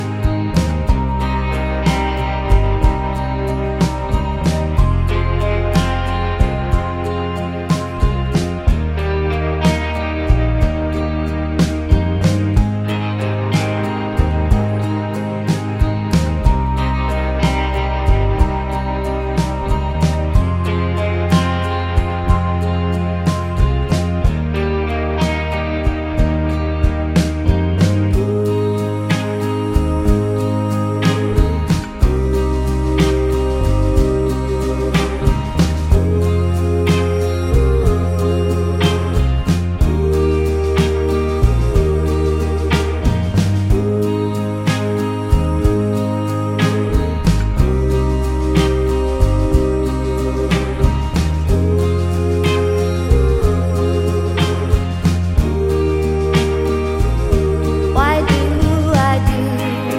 for solo singer Pop (2010s) 4:14 Buy £1.50